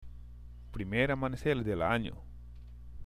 ＜発音と日本語＞
（プリメール　アマネセール　デル　アニョ）